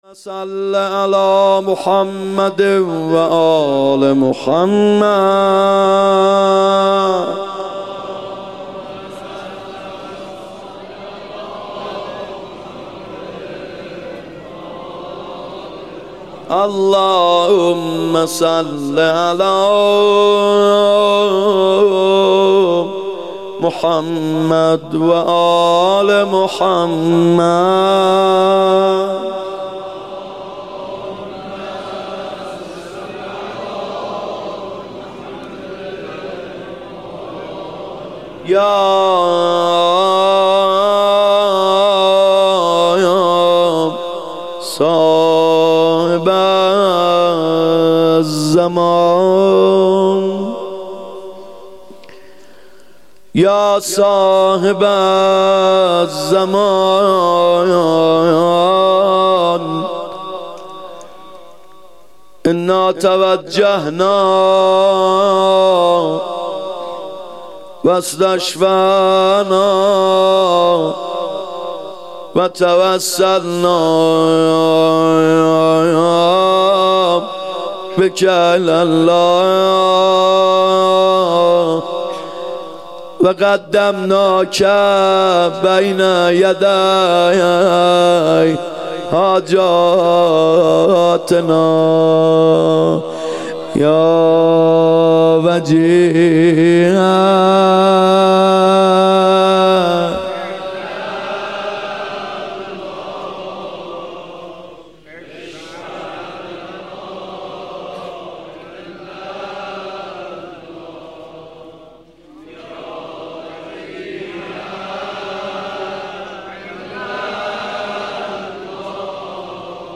محرم 94(هیات یا مهدی عج